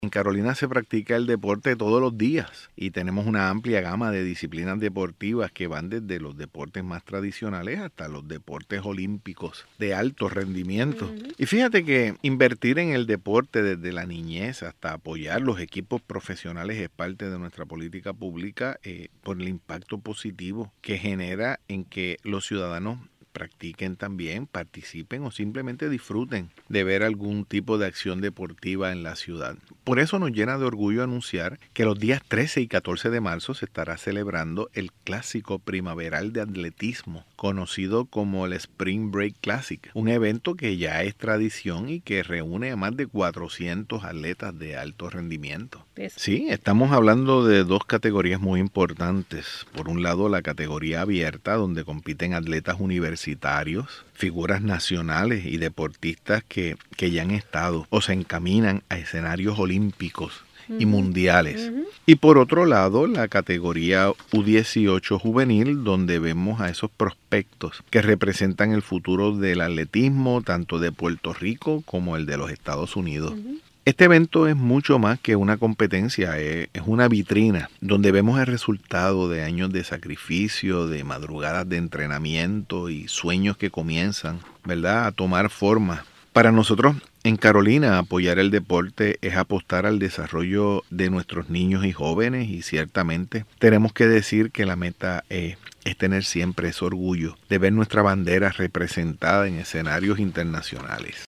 Alcalde de Carolina anuncia que el 13 y 14 de marzo se estará celebrando el Clásico Primaveral de  Atletismo, conocido como el Spring Break Clásico (sonido)